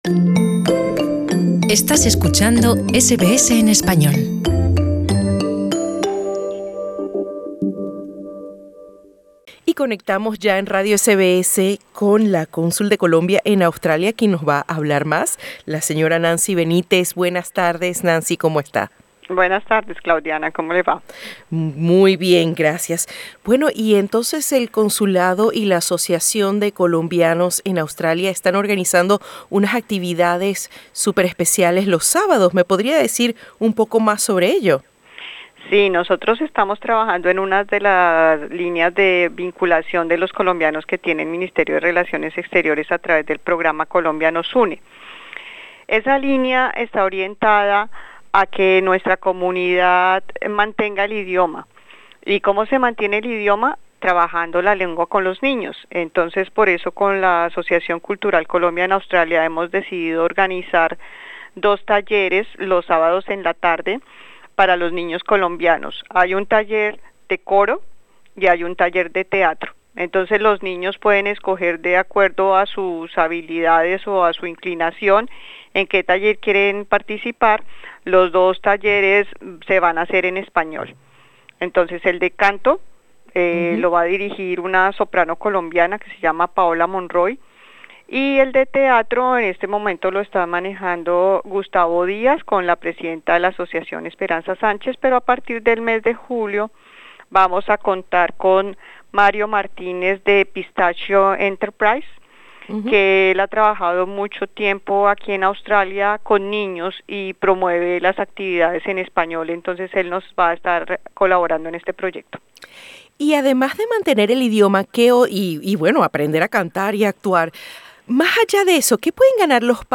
Es precisamente por esta razón que el Consulado General de Colombia en Sídney y la Asociación Cultural Colombia en Australia, han organizado una serie de clases de teatro infantil y coro para hijos de padres colombianos. Así que si eres colombiano y tienes hijos menores de edad, escucha esta entrevista con la Cónsul de Colombia en Australia, Nancy Benítez para conocer los detalles.